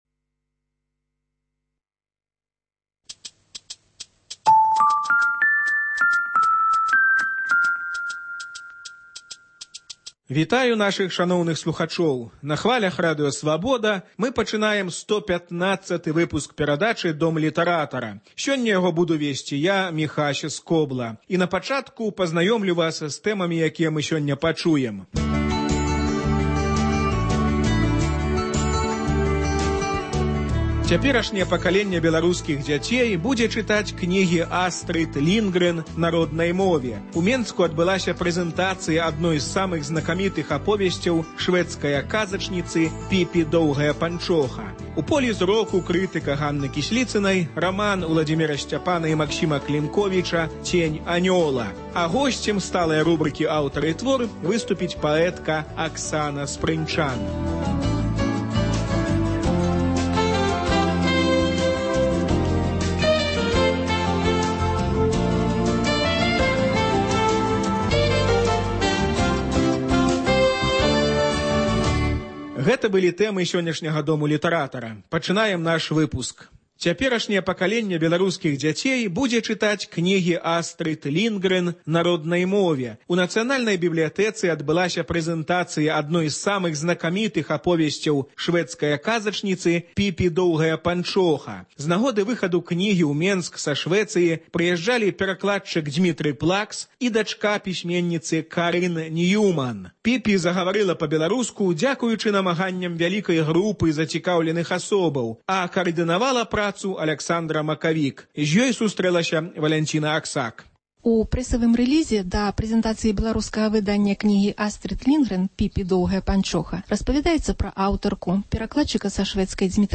Літаратурны агляд